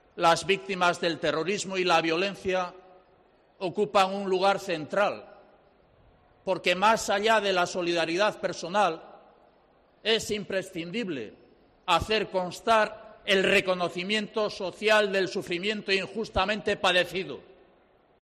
En la celebración del Aberri Eguna del PNV, Ortuzar y Urkullu han estado acompañados por la ejecutiva del partido, los consejeros de ese partido del Gobierno Vasco, así como representantes de otras instituciones vascas, en un acto en la plaza Nueva de Bilbao al que han asistido miles de simpatizantes y afiliados.